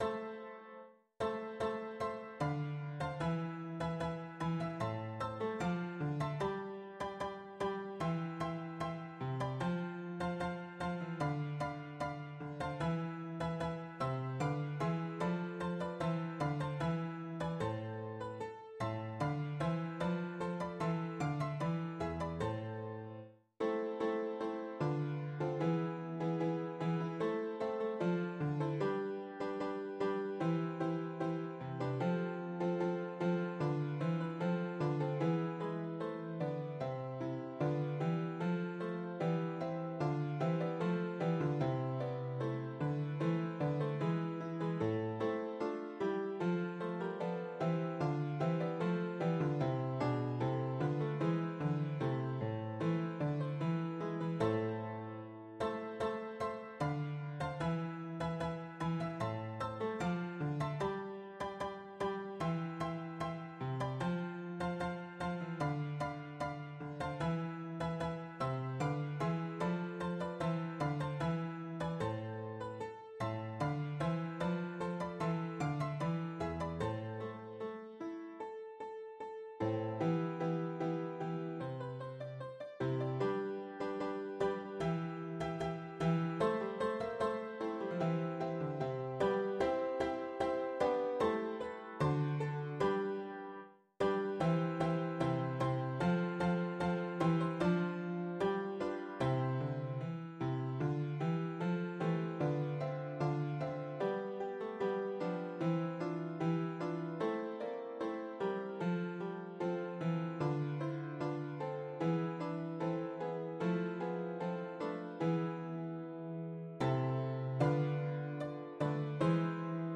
Number of voices: 4vv   Voicing: SATB
Genre: SacredCantata
Instruments: strings, bassoon, continuo